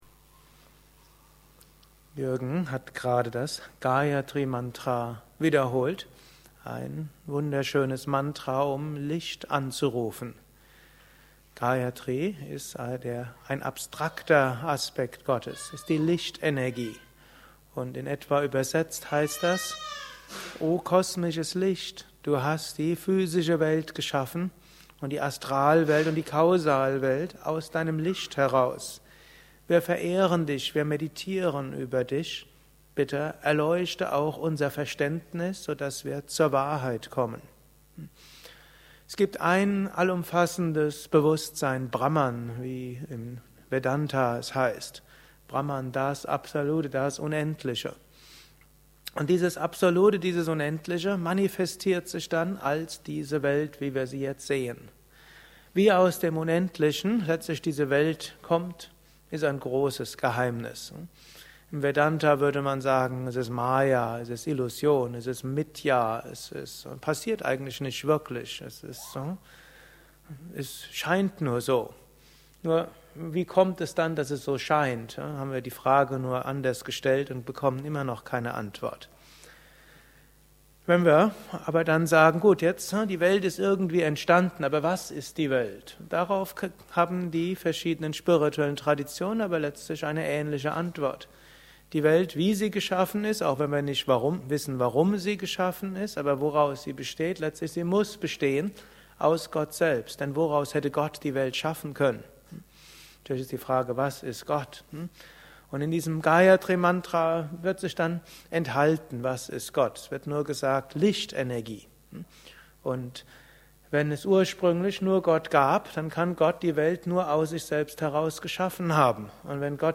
Gelesen im Anschluss nach einer Meditation im Haus Yoga Vidya Bad Meinberg.